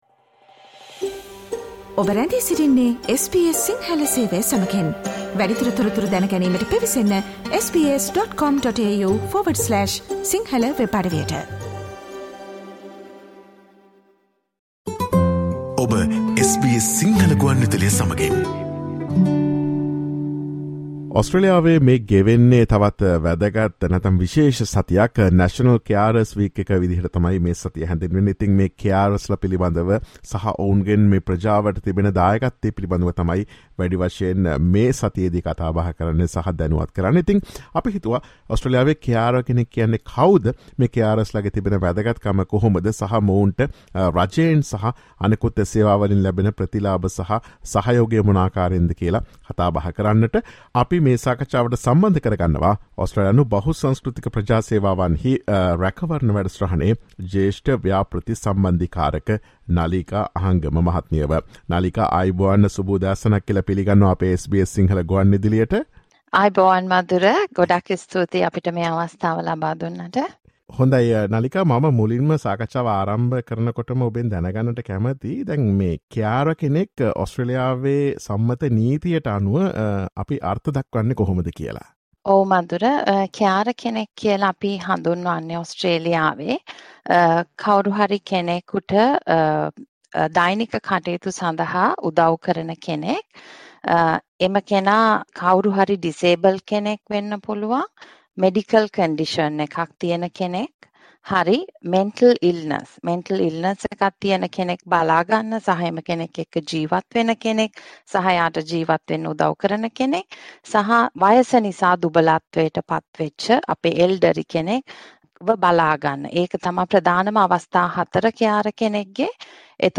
Focussing on National Carers Week, listen to SBS Sinhala Radio's discussion about who can become a Carer in Australia and the benefits they receive.